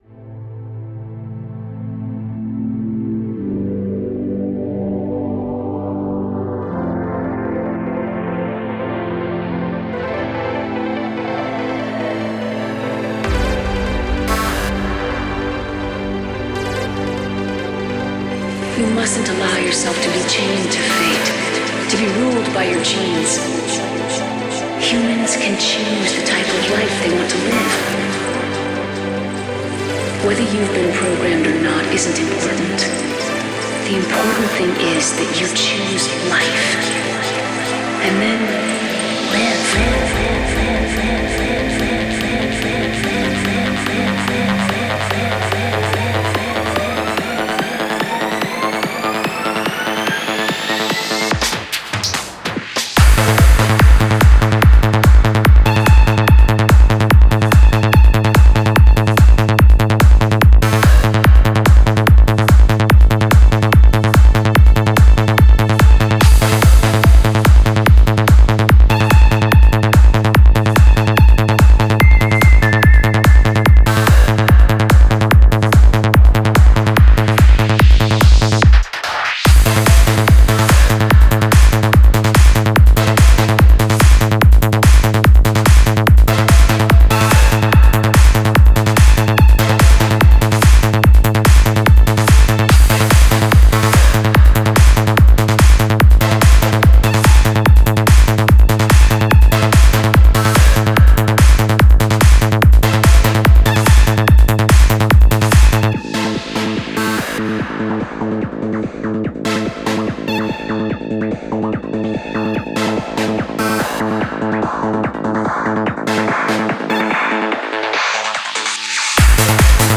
Style: FullOn